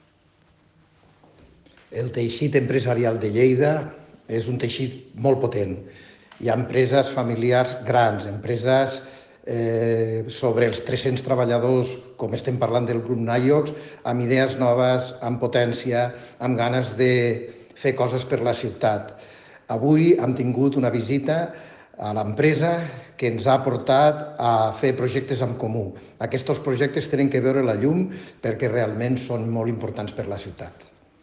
Tall de veu del tinent d'alcalde i regidor de Promoció Econòmica Paco Cerdà sobre la trobada amb responsables del grup Nayox.